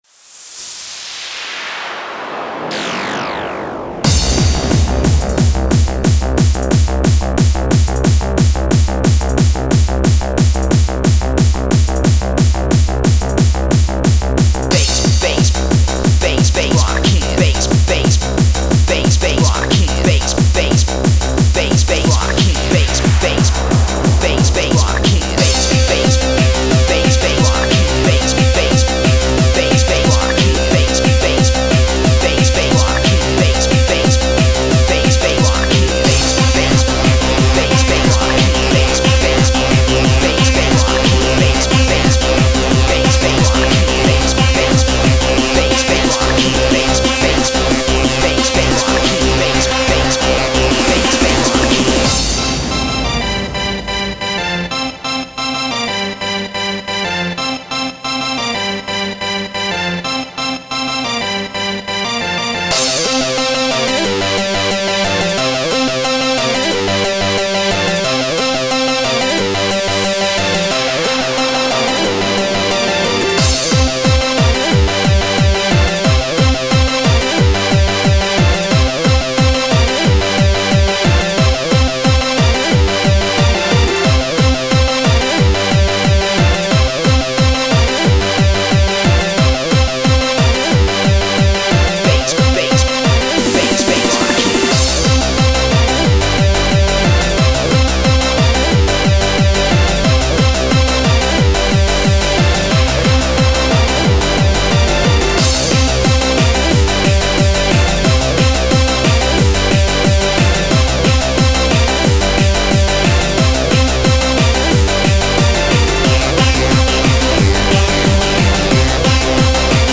hardcore, rave, bouncy techno